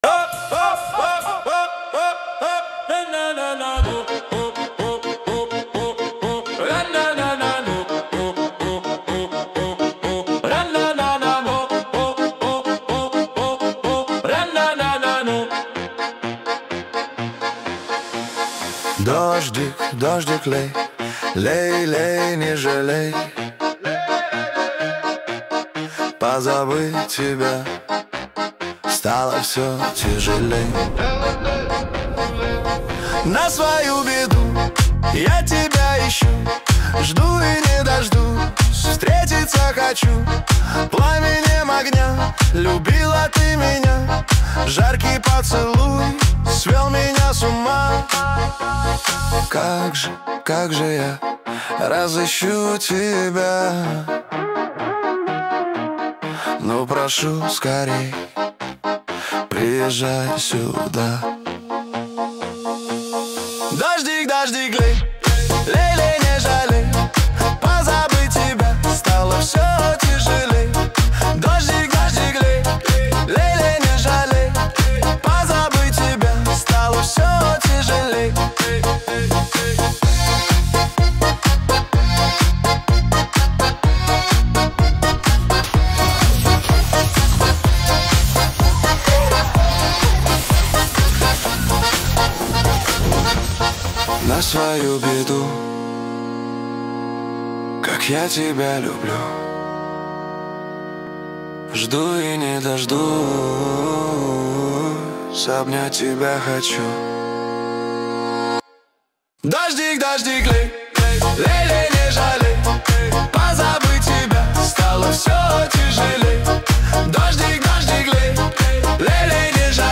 13 декабрь 2025 Русская AI музыка 74 прослушиваний